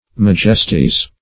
Majesty \Maj"es*ty\, n.; pl. Majesties.